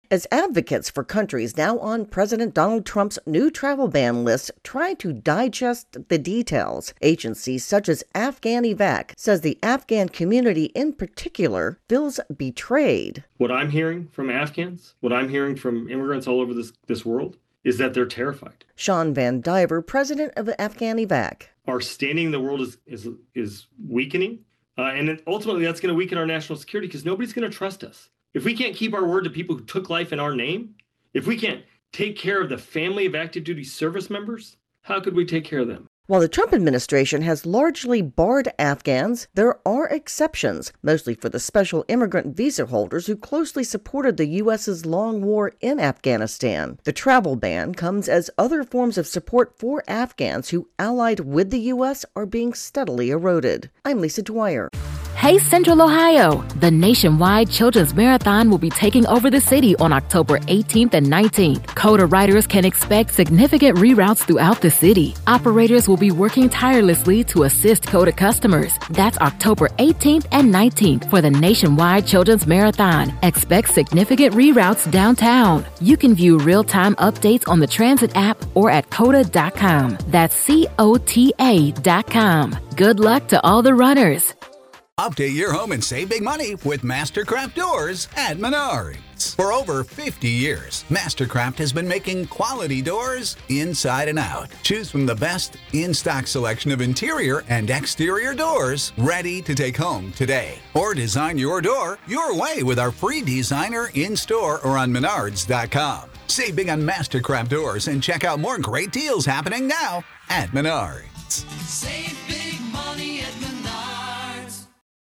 reports on reaction to the Trump administrations new travel ban list.